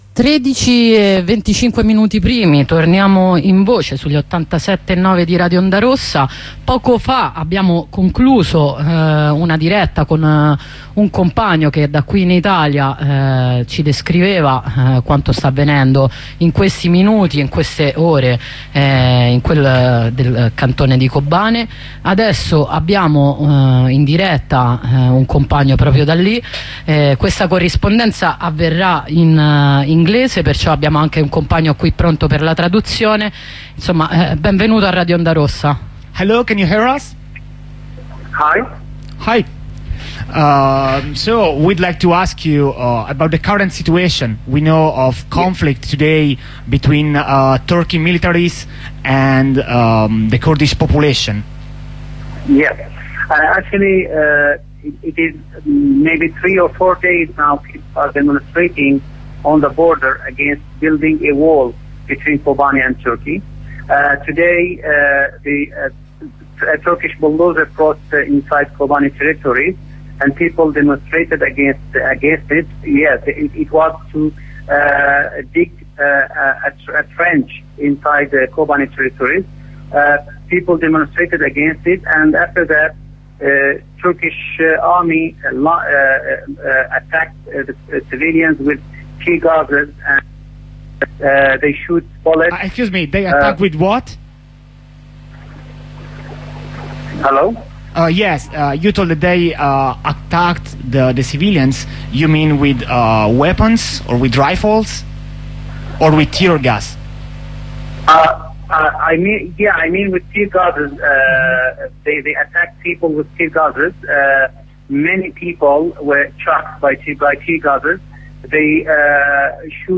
Corrispondenza da Kobane
Corrispondenza in inglese con un abitante del cantone di Kobane che ci racconta degli scontri tra la popolazione e l'esercito turco al confine.